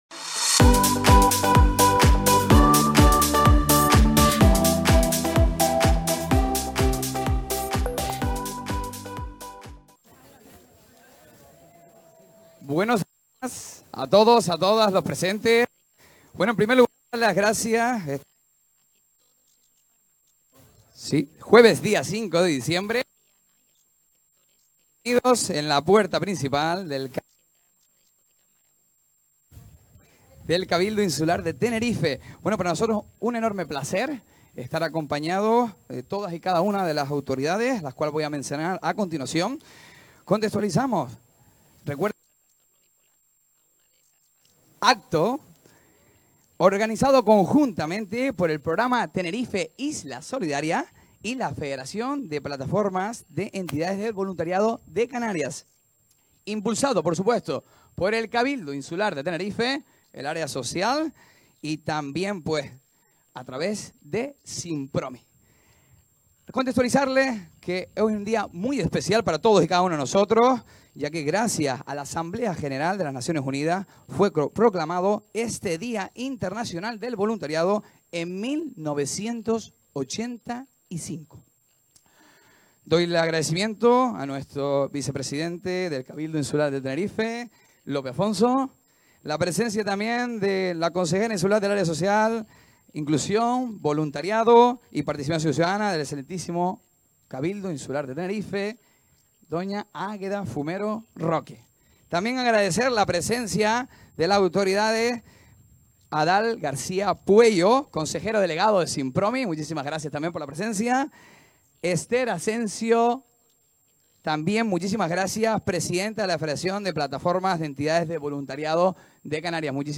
El Cabildo de Tenerife conmemoró hoy (jueves 5) el Día Mundial del Voluntariado, con la lectura de un manifiesto, organizado a través del programa Tenerife Isla Solidaria, en colaboración con la Federación Plataforma de Entidades de Voluntariado...